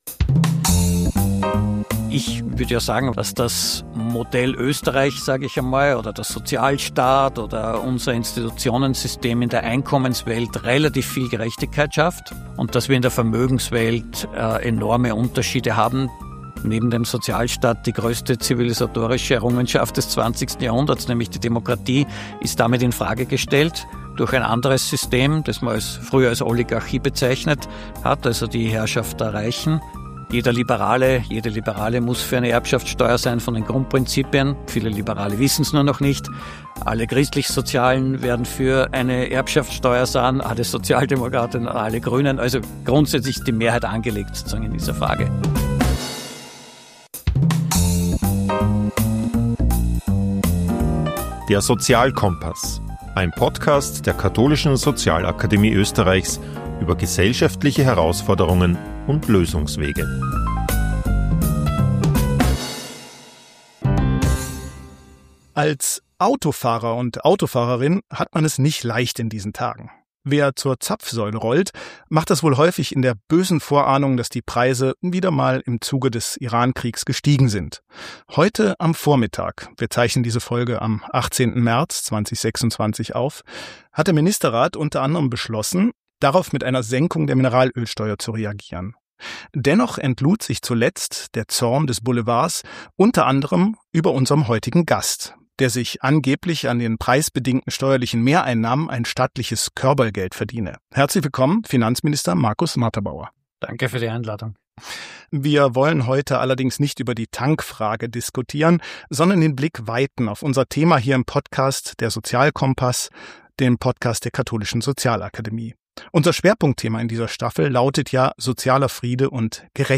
Das ist die zentrale These des österreichischen Finanzministers Markus Marterbauer. Im Gespräch erklärt er, warum Österreich in der Einkommenswelt vergleichsweise fair ist, in der Vermögenswelt aber nicht: Das reichste Prozent der Haushalte besitzt mehr als 40 Prozent des gesamten Vermögens.